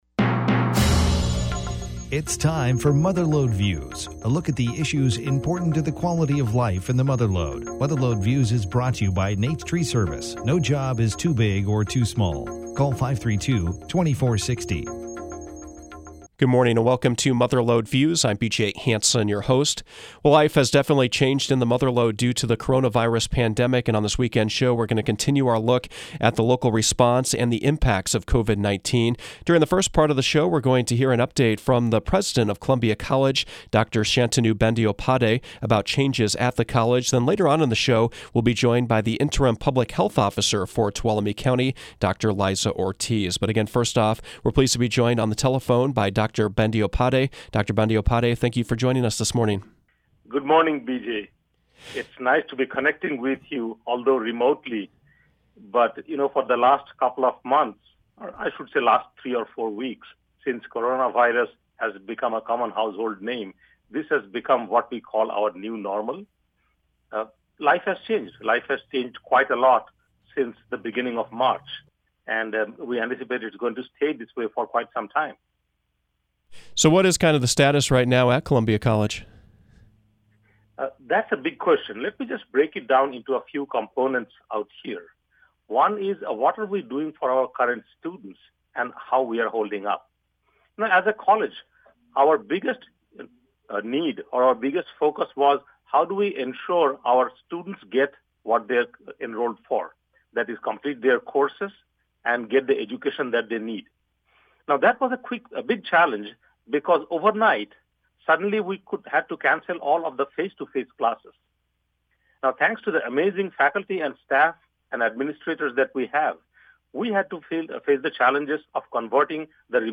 The first part of the show featured an interview